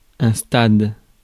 Ääntäminen
Synonyymit période Ääntäminen France: IPA: [stad] Haettu sana löytyi näillä lähdekielillä: ranska Käännös 1. staadion Suku: m .